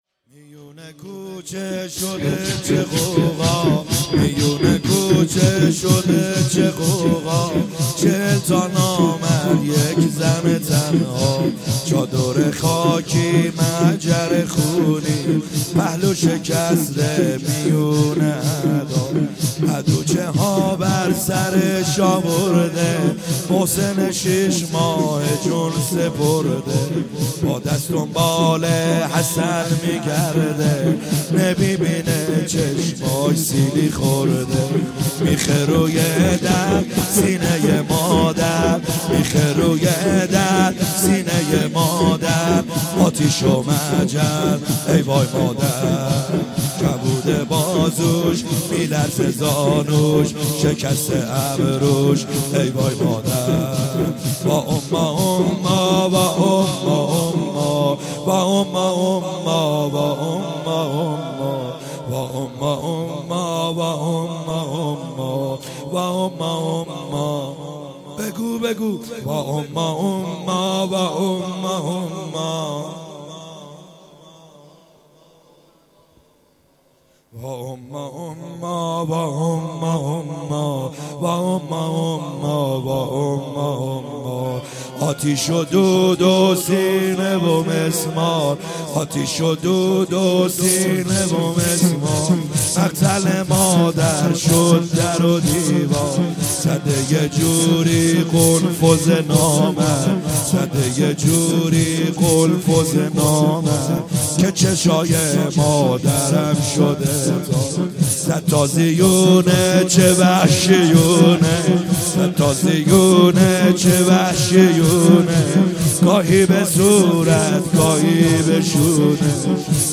خیمه گاه - بیرق معظم محبین حضرت صاحب الزمان(عج) - لطمه زنی | میونه کوچه شده